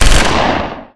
turret-3.wav